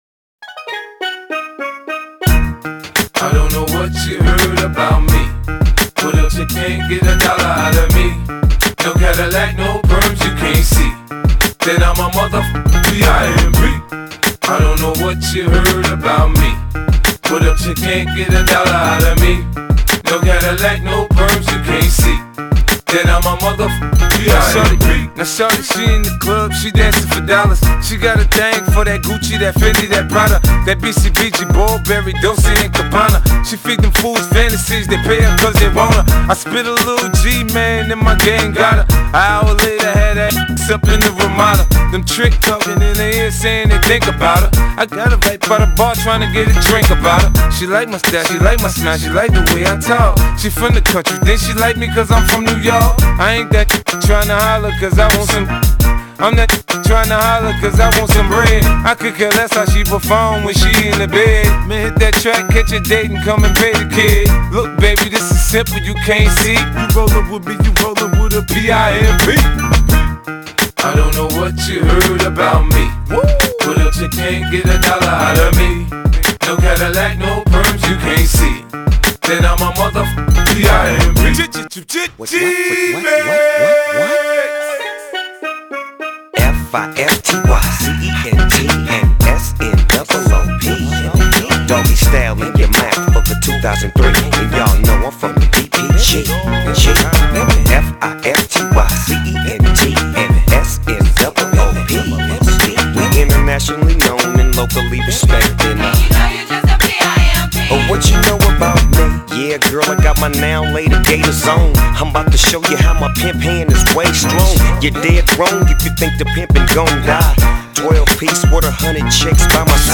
Hip Hop Rap!